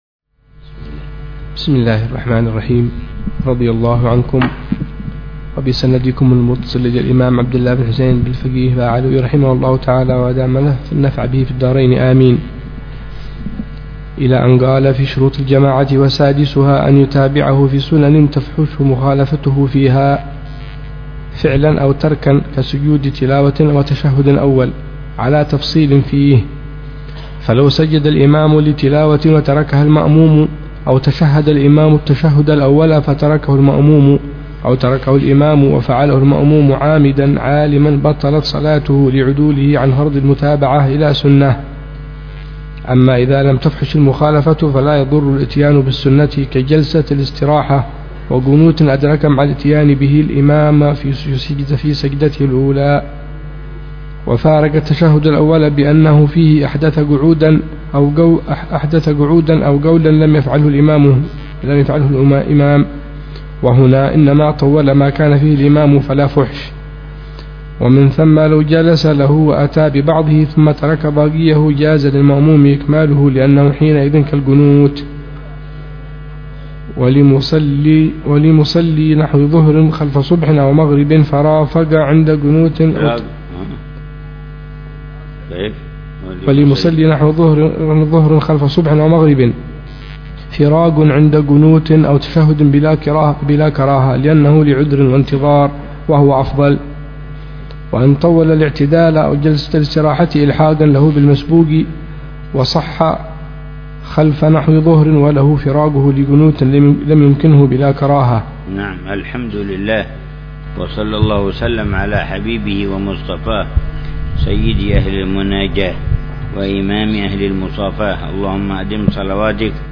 شرح الحبيب عمر بن حفيظ على كتاب كفاية الراغب شرح هداية الطالب إلى معرفة الواجب للإمام العلامة عبد الله بن الحسين بن عبد الله بلفقيه.